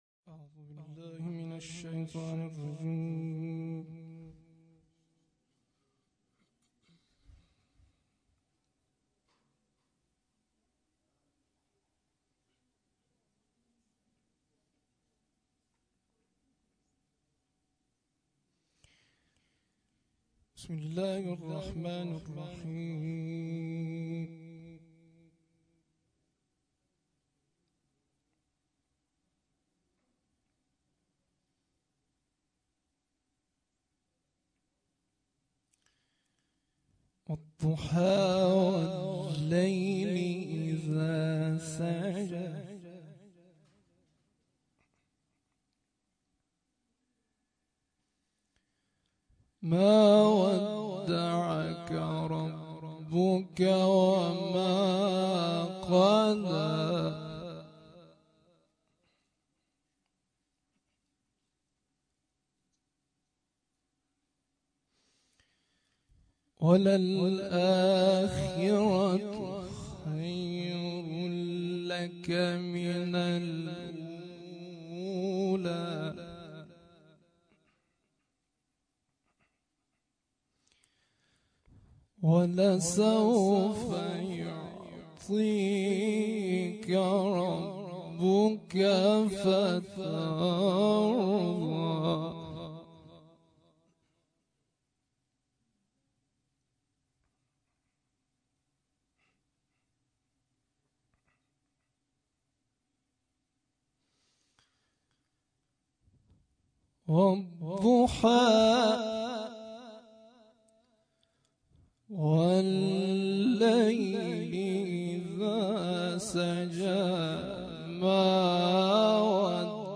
شب اول ویژه برنامه فاطمیه دوم ۱۴۳۹